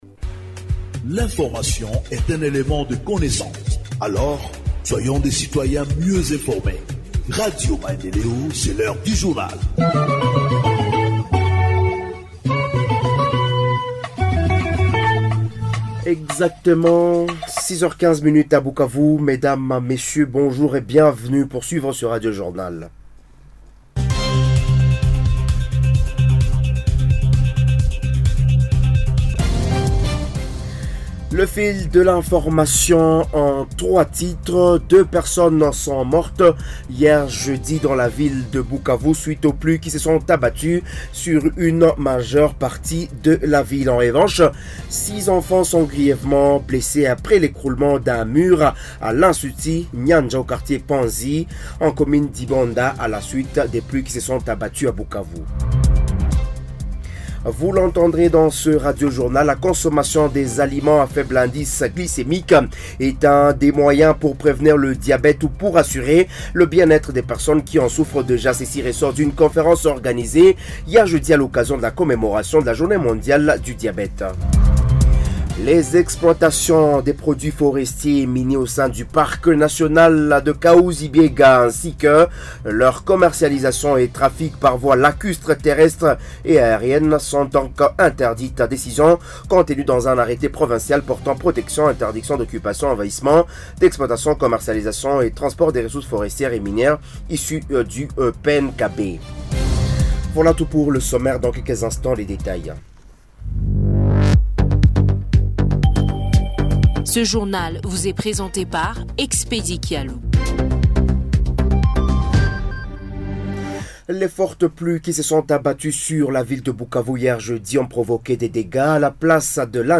Journal en Français du 15 novembre 2024 – Radio Maendeleo